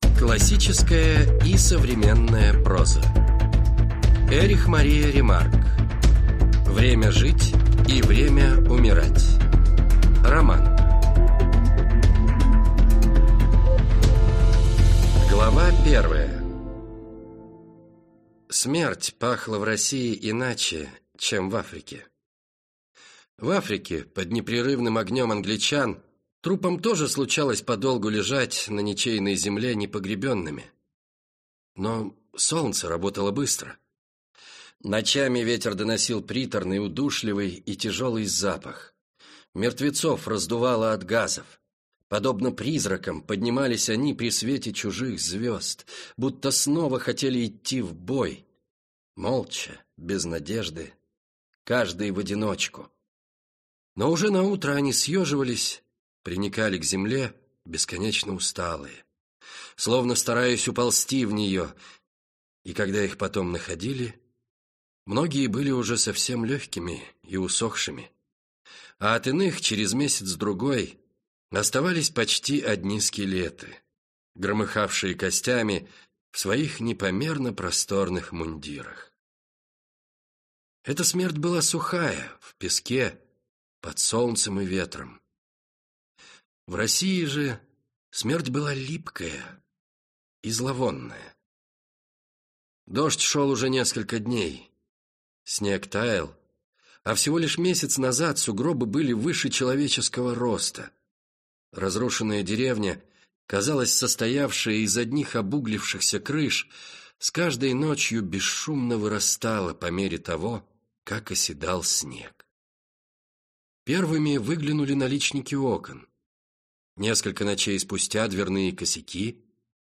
Аудиокнига Время жить и время умирать | Библиотека аудиокниг